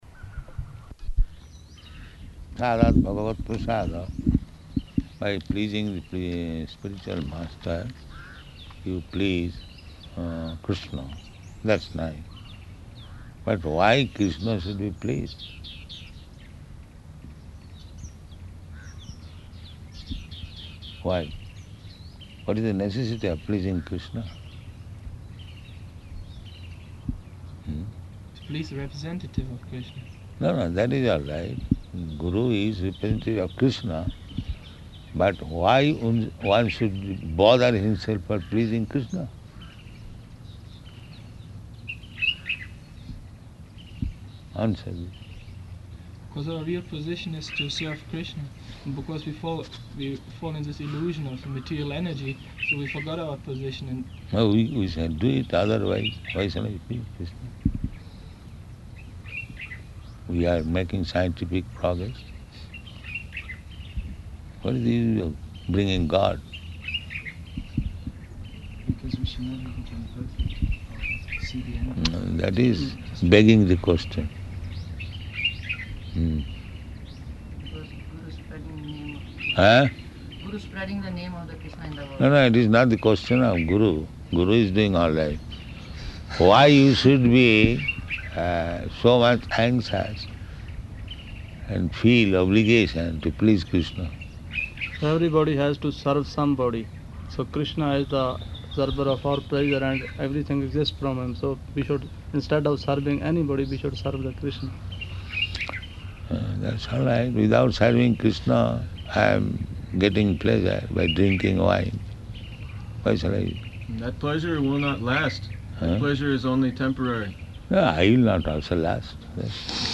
Room Conversation
Room Conversation --:-- --:-- Type: Conversation Dated: October 29th 1975 Location: Nairobi Audio file: 751029R2.NAI.mp3 Prabhupāda: ...prasādād bhagavat-prasādaḥ.